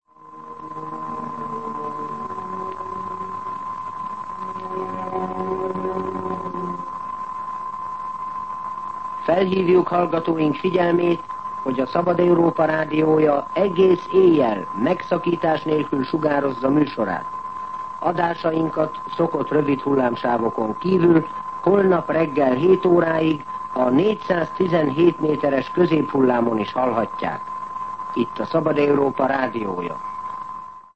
Frekvenciaismertetés